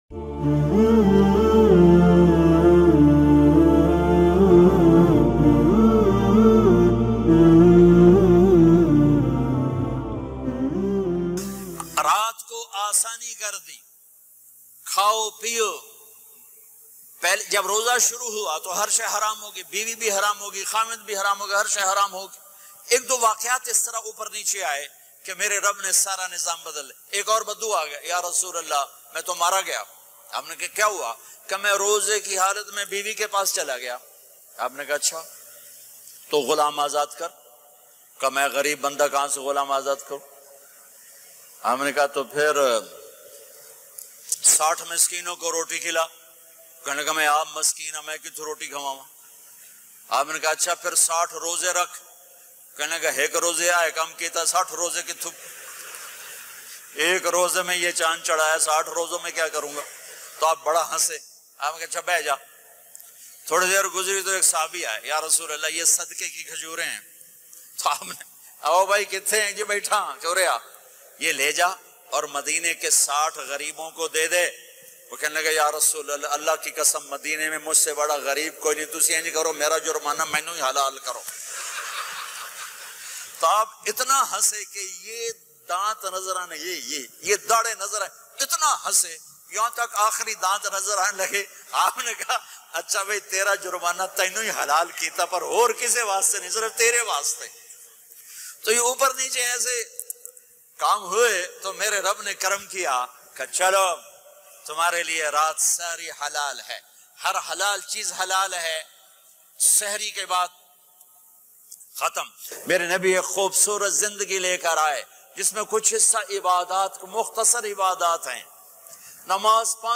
Ramzan Main Biwi Ke Pass Jana Jaiz Hai latest bayan
Ramzan Main Biwi Ke Pass Jana Jaiz Hai Maulana Tariq Jameel latest bayan mp3 free download.